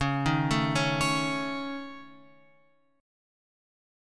C# Suspended 2nd Sharp 5th